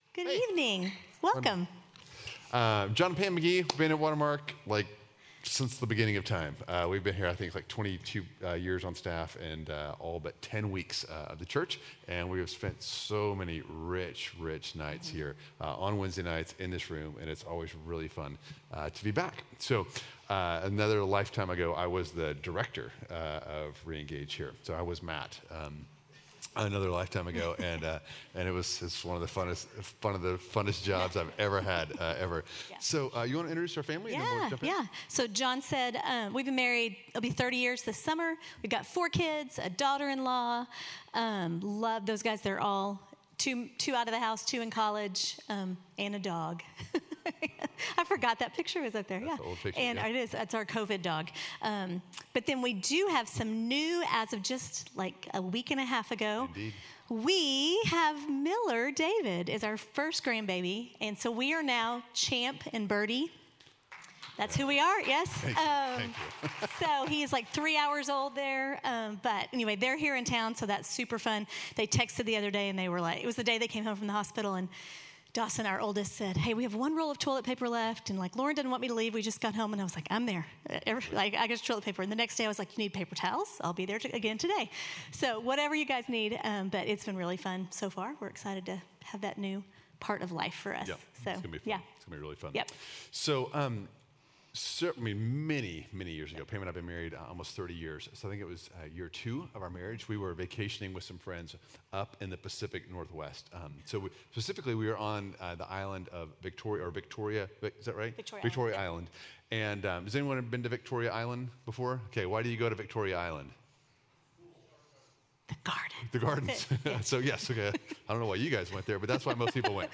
re|engage Teaching